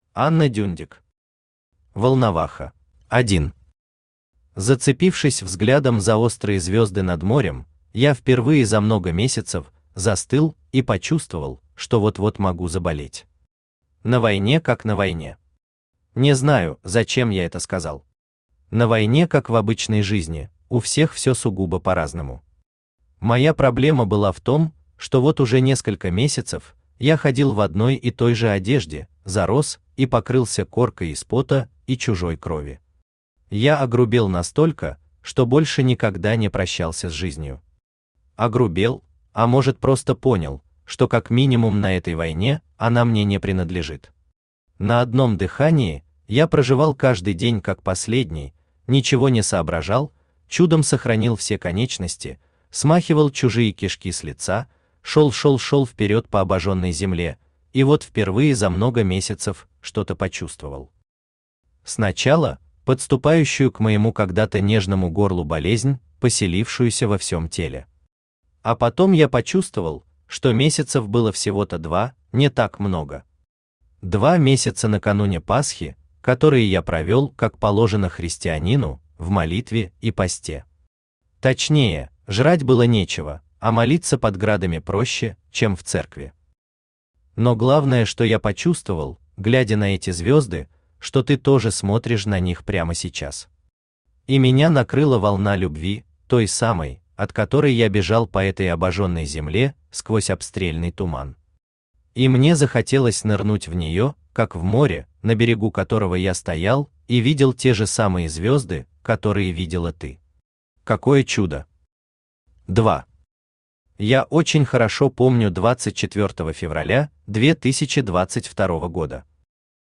Аудиокнига Волноваха | Библиотека аудиокниг
Aудиокнига Волноваха Автор Анна Анатольевна Дюндик Читает аудиокнигу Авточтец ЛитРес.